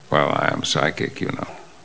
X-Men Movie Sound Bites